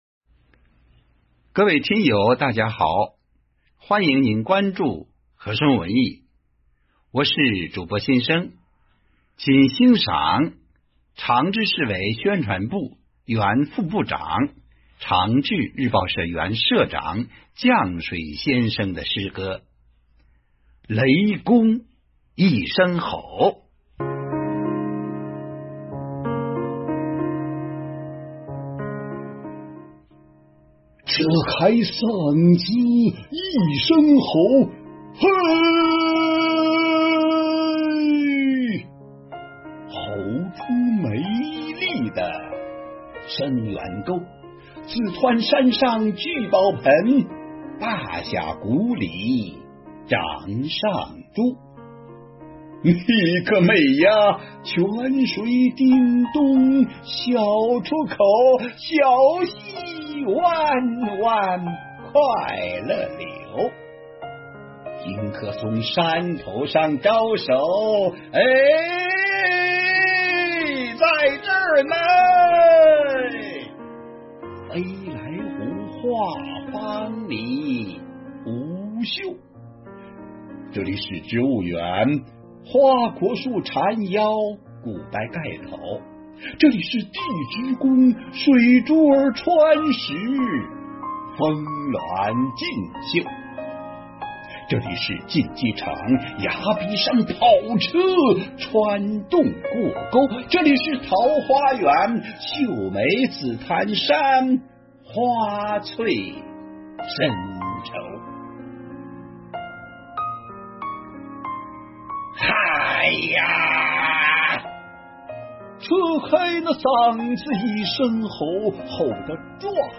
-诵读者简介